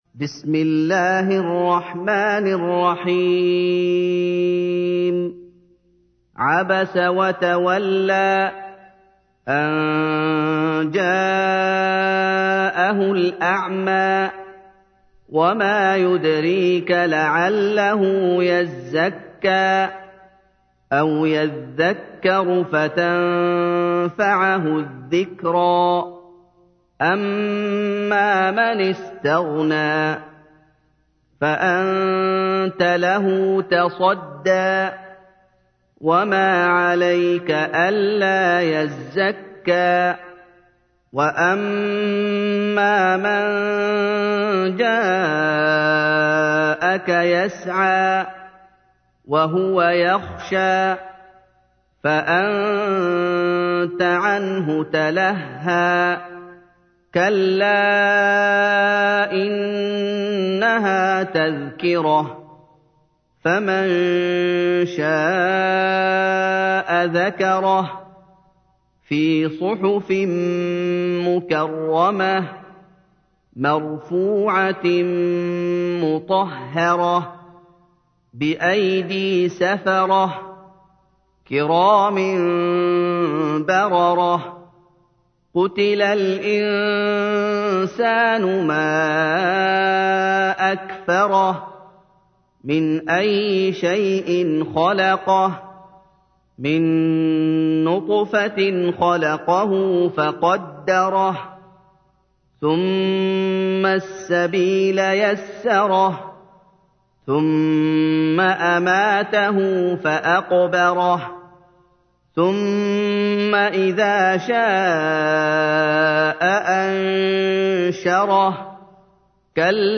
تحميل : 80. سورة عبس / القارئ محمد أيوب / القرآن الكريم / موقع يا حسين